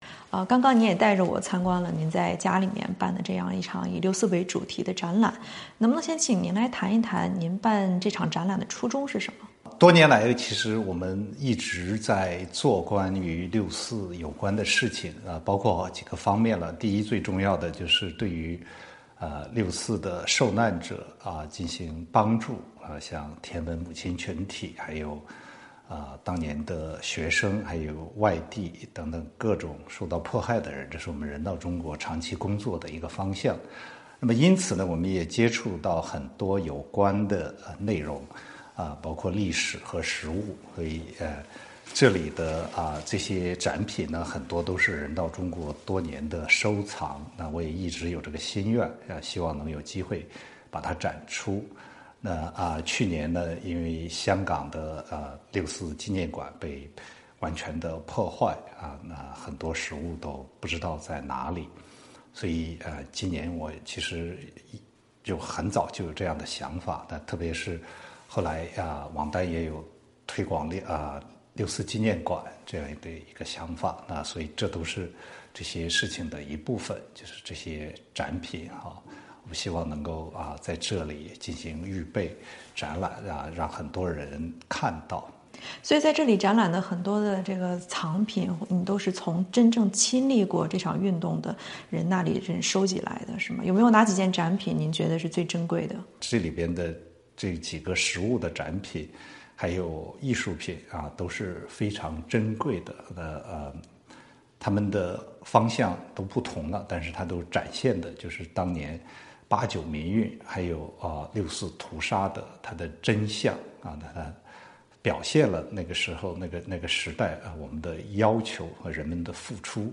VOA专访: 周锋锁: 对于“六四”的功过是非，史实具有最强大的说服力